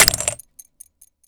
grenade_hit_03.WAV